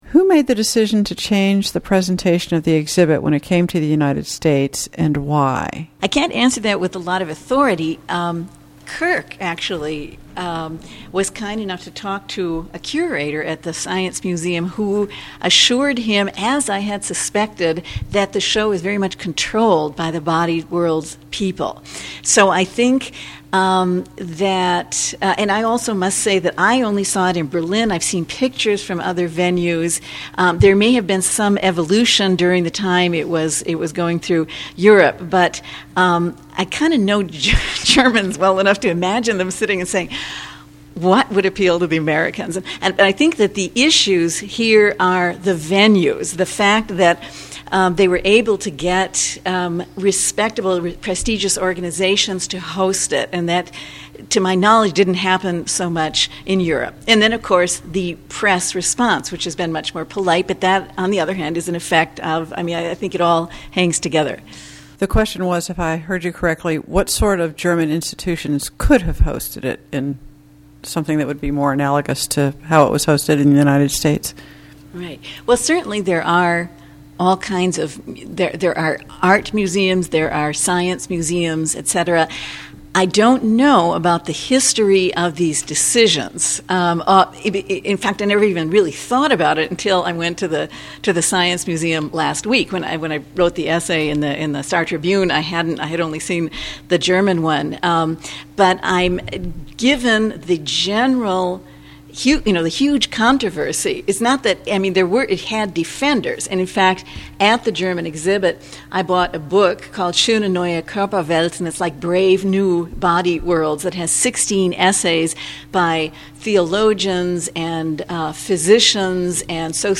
Lecture Audios